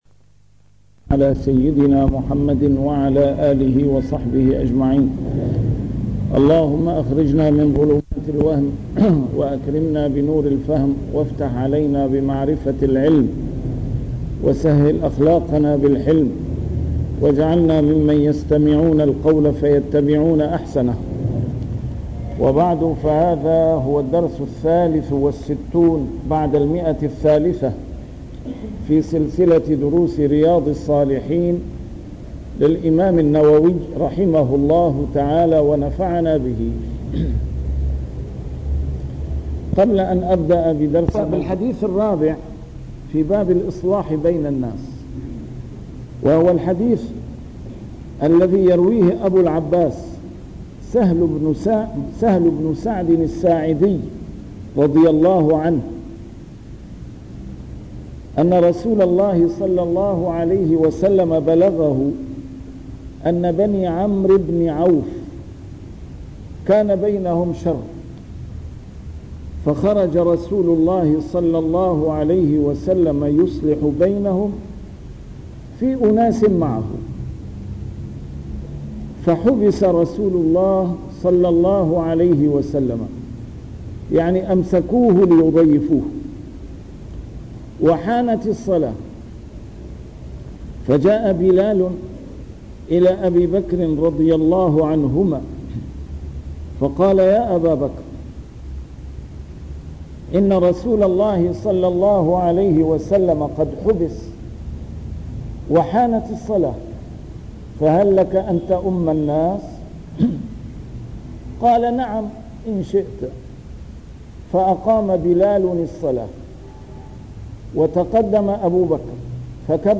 A MARTYR SCHOLAR: IMAM MUHAMMAD SAEED RAMADAN AL-BOUTI - الدروس العلمية - شرح كتاب رياض الصالحين - 363- شرح رياض الصالحين: الإصلاح بين الناس